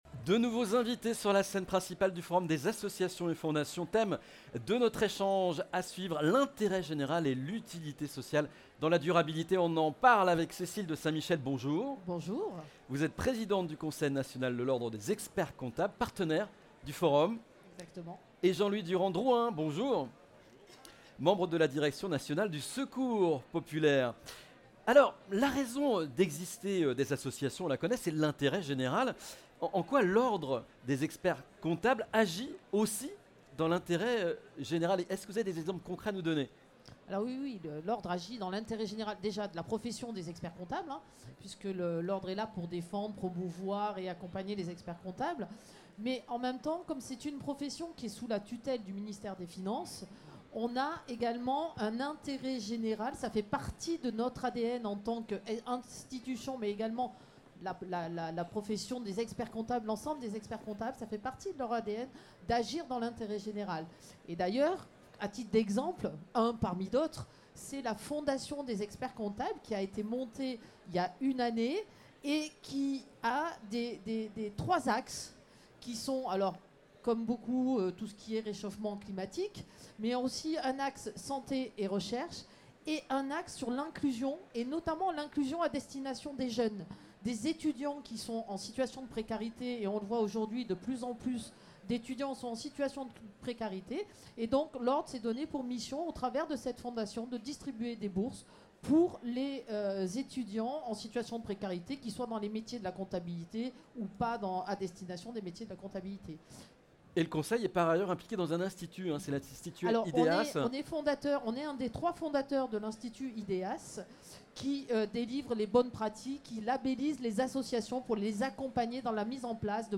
L’Ordre des experts-comptables, partenaire officiel du Forum National des Associations et Fondations (FNAF) a participé cette année à la 17ème édition, le 18 octobre au Palais des Congrès.